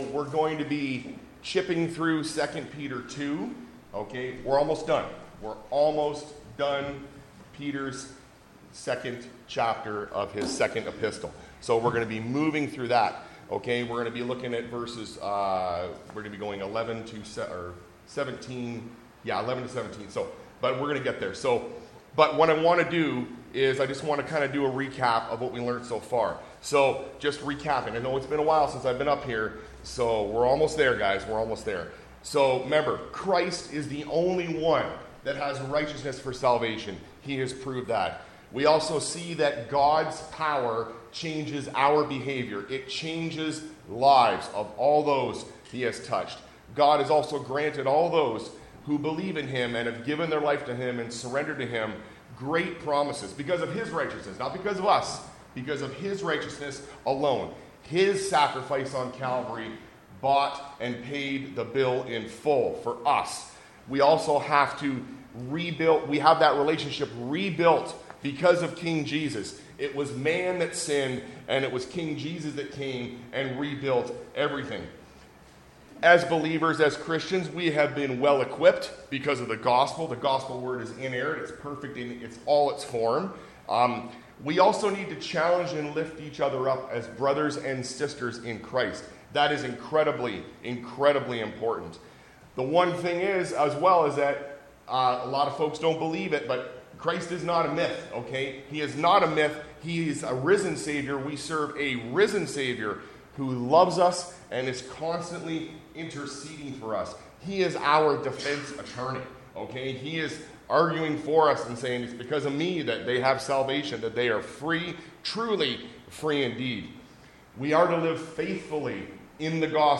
A message from the series "The Word Together."